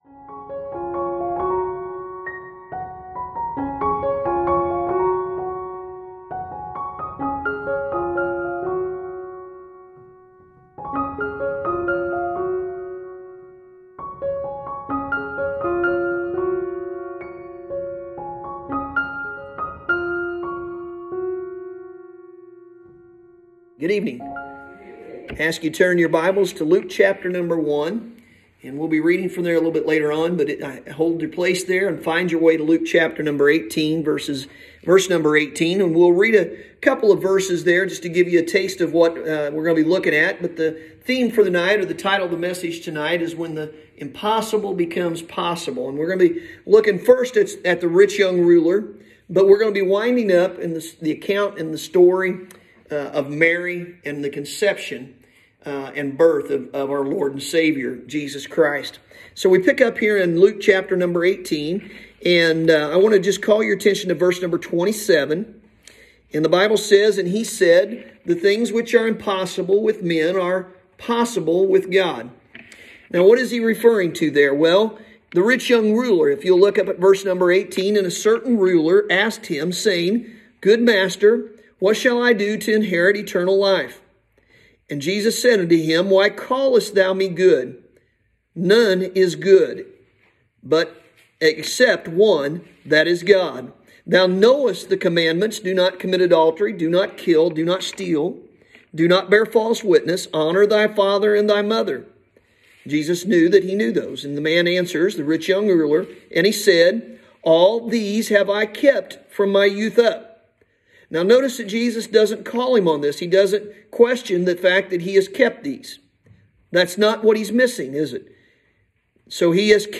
Sunday Evening – December 20th, 2020